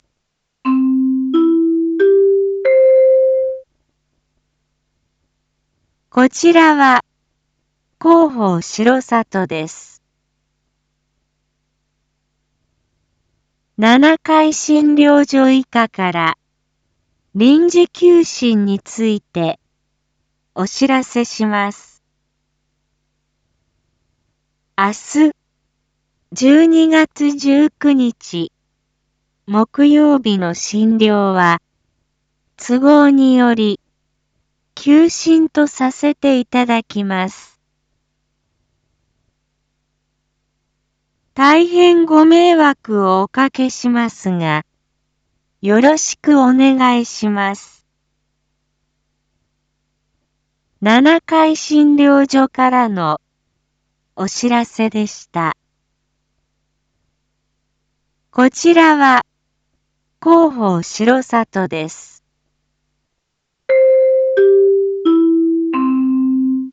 一般放送情報
Back Home 一般放送情報 音声放送 再生 一般放送情報 登録日時：2024-12-18 19:01:08 タイトル：七会診療所休診① インフォメーション：こちらは広報しろさとです。 七会診療所医科から臨時休診についてお知らせします。